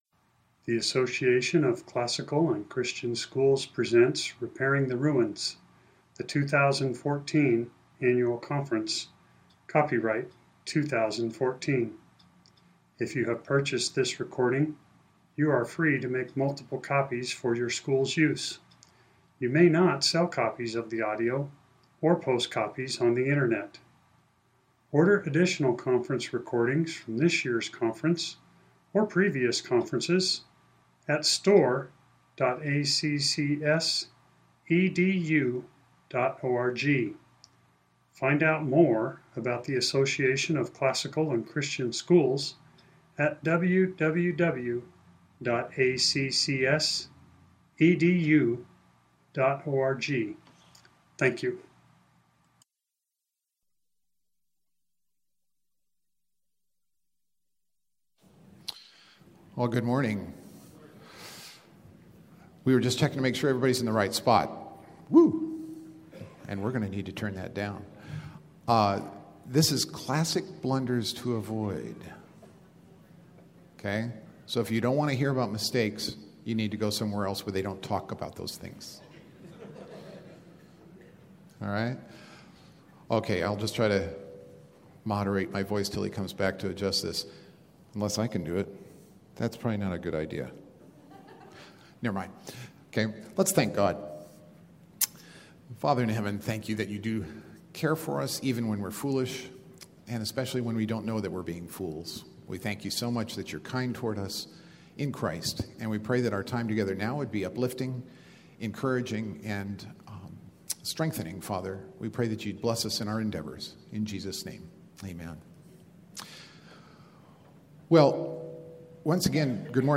2014 Foundations Talk | 1:01:46 | All Grade Levels, General Classroom